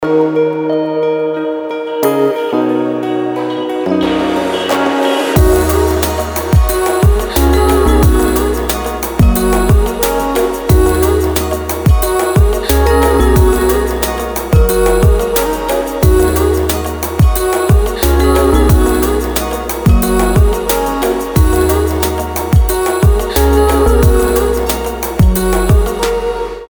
• Качество: 320, Stereo
Electronic
спокойные
relax